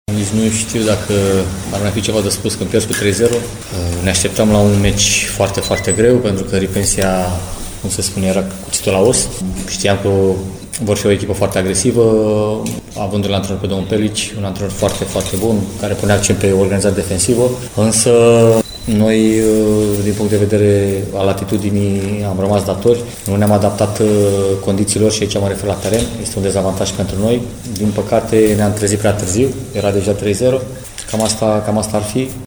Antrenorul bucureștenilor, Marius Măldărășanu, spune că Metalo s-a trezit prea târziu: